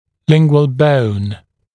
[‘lɪŋgwəl bəun][‘лингуэл боун]подъязычная кость